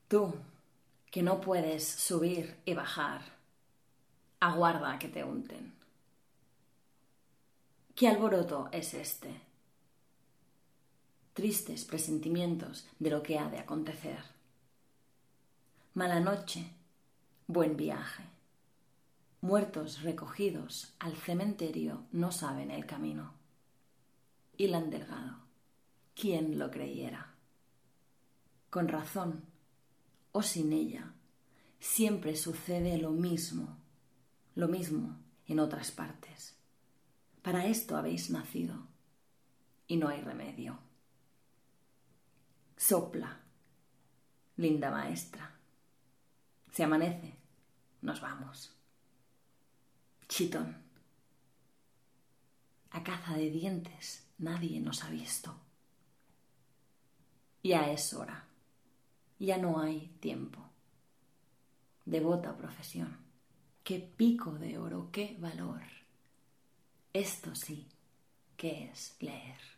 La lectura en veu alta del poema acompanya aquesta nova disposició dels gravats, que transcorre entre el relat caricaturesc i jocós i l'escepticisme murri: